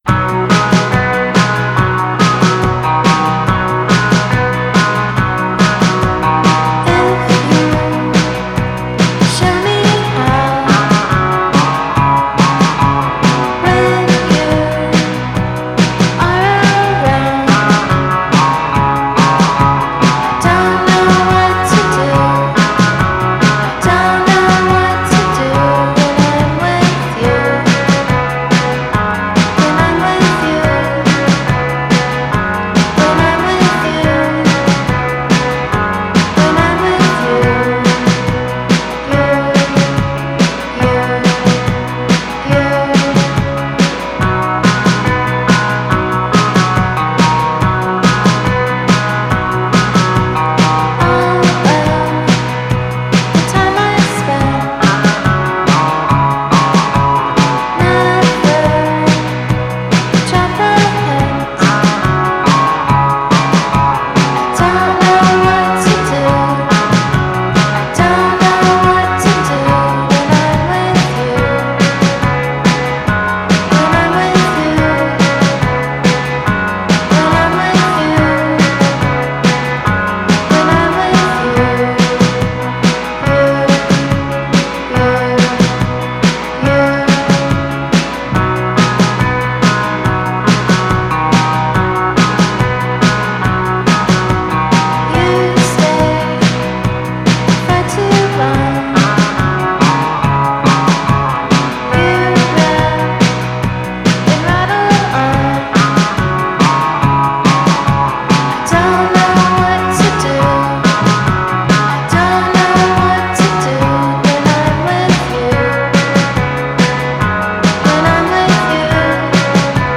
Spector soul with a lot of dreamy spice.
singer
bass player
Good solid Spector 50’s pop track